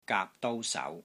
Trapping-Hands-GAP3-DOU1-SAU2-_mdash-Wing-Chun-Elements_-Glossary.mp3